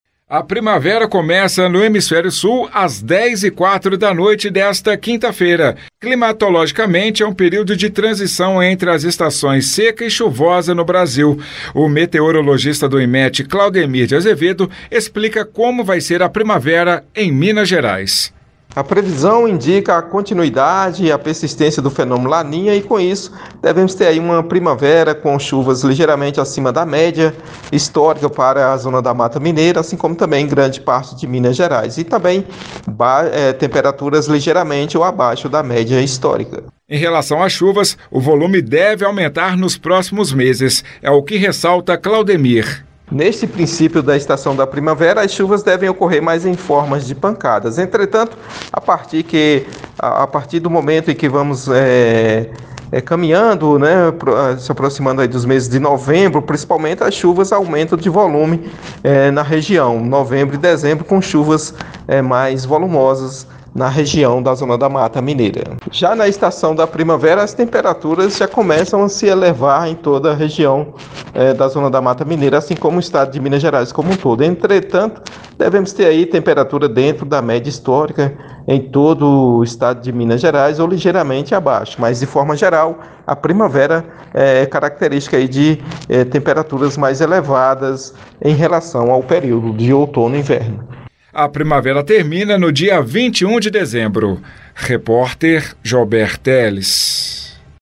Primavera: Meteorologista explica ação do fenômeno La Niña.